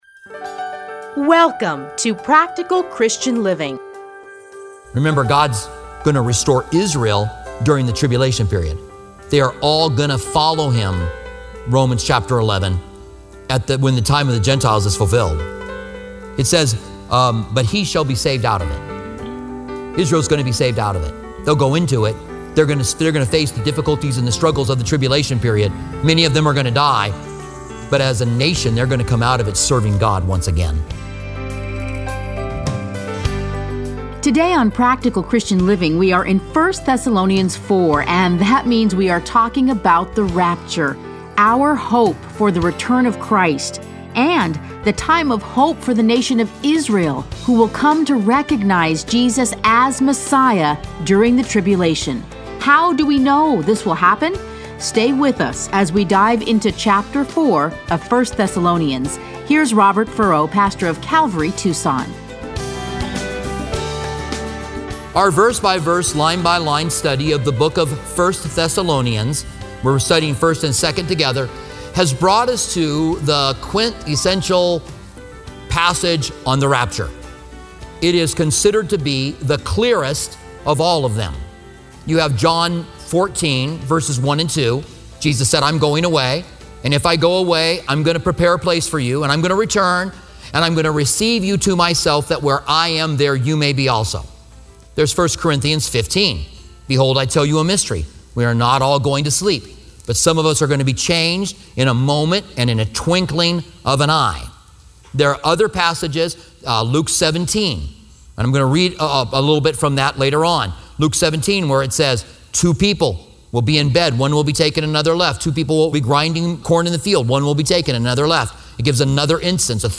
Listen to a teaching from 1 Thessalonians 4:13-18.